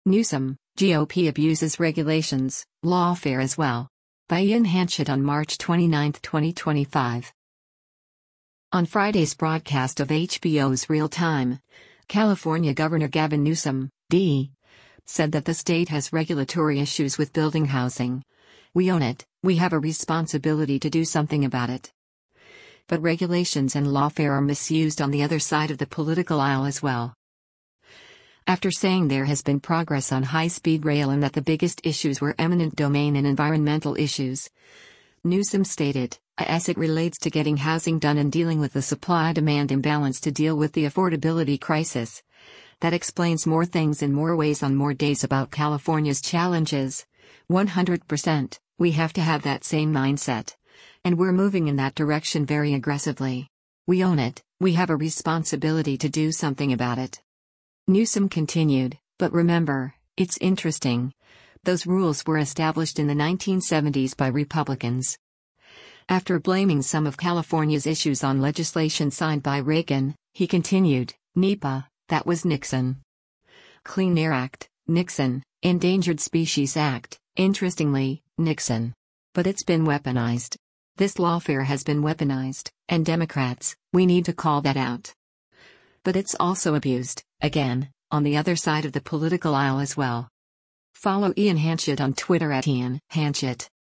On Friday’s broadcast of HBO’s “Real Time,” California Gov. Gavin Newsom (D) said that the state has regulatory issues with building housing, “We own it, we have a responsibility to do something about it.” But regulations and lawfare are misused “on the other side of the political aisle as well.”